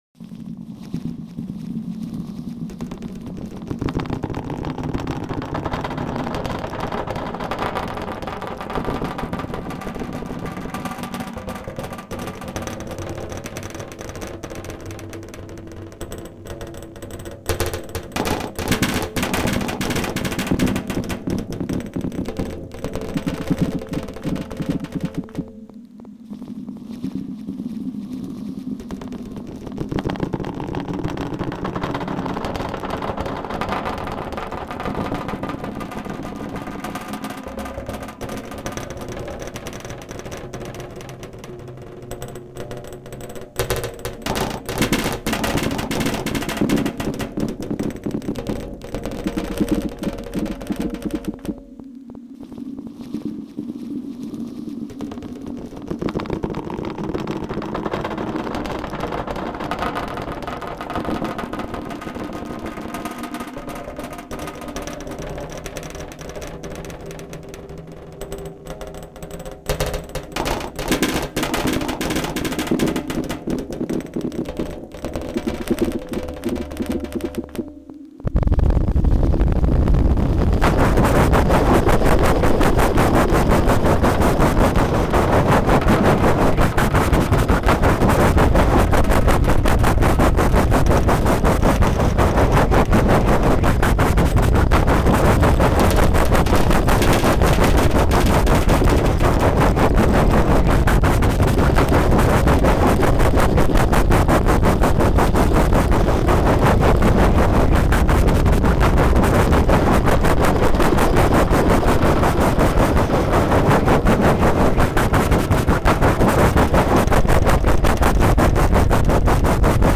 with an oblique glitchy sounding circular movement